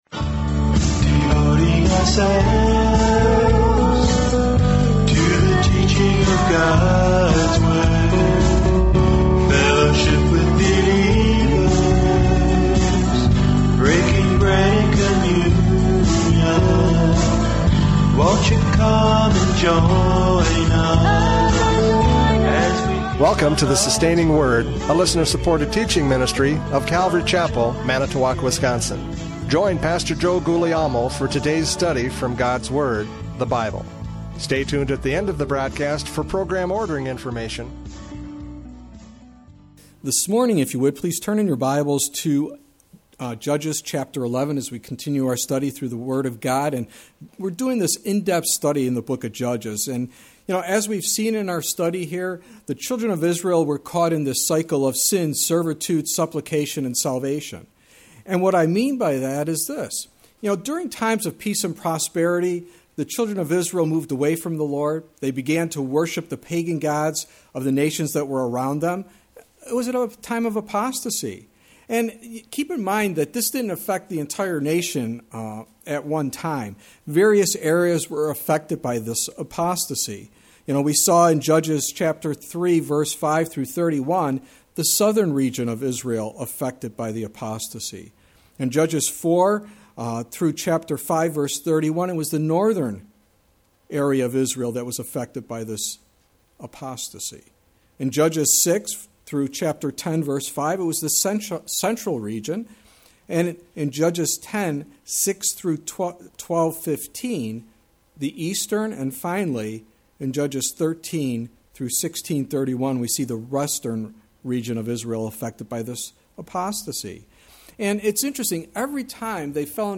Judges 11:1-11 Service Type: Radio Programs « Judges 10 The Decay of a Nation!